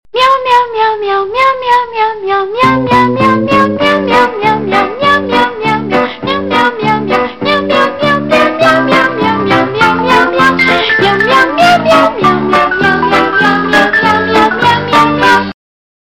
Cŕŕn Macacie zvonenie 0:10